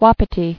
[wap·i·ti]